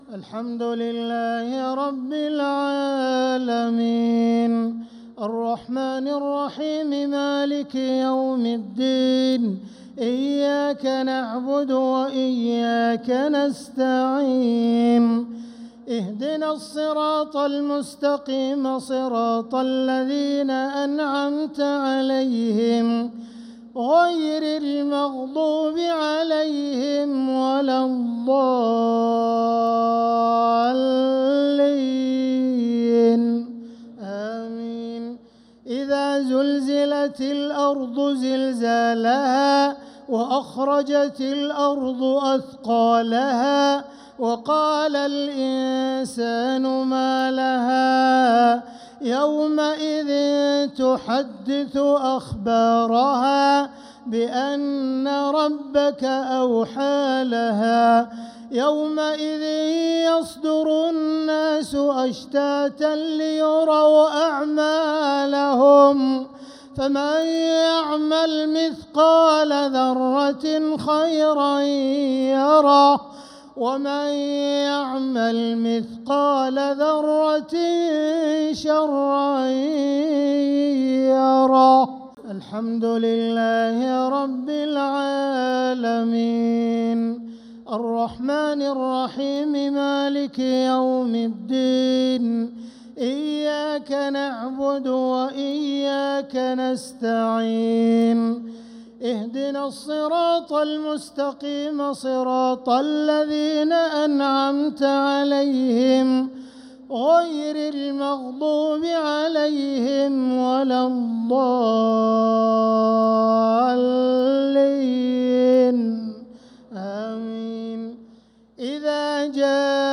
صلاة الشفع و الوتر ليلة 7 رمضان 1446هـ | Witr 7th night Ramadan 1446H > تراويح الحرم المكي عام 1446 🕋 > التراويح - تلاوات الحرمين